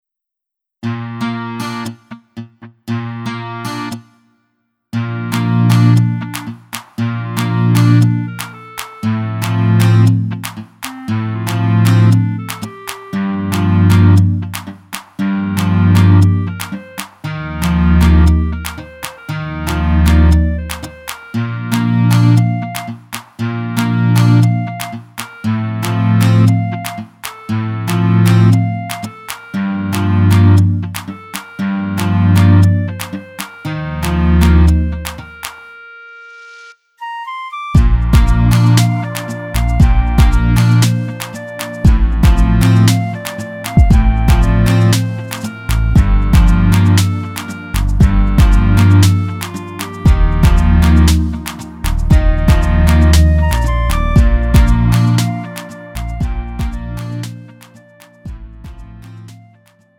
음정 원키
장르 가요 구분 Lite MR